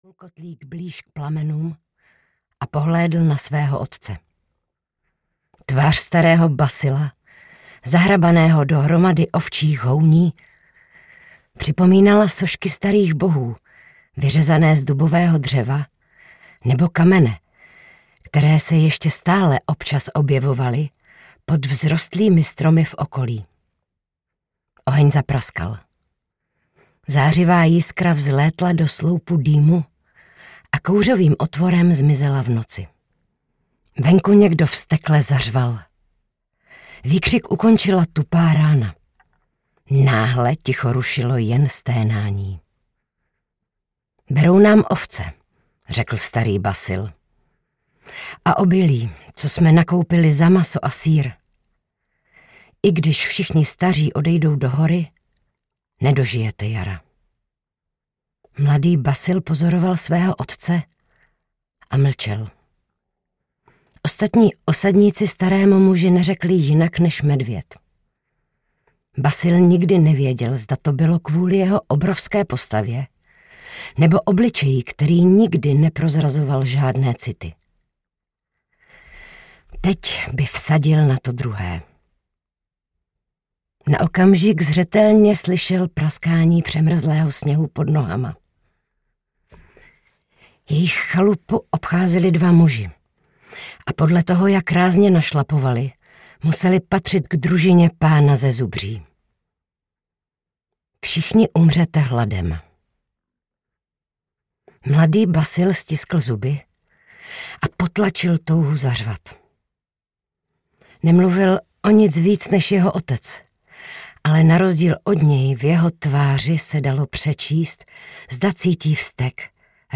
BASIL – Valašský vojvoda audiokniha
Ukázka z knihy
basil-valassky-vojvoda-audiokniha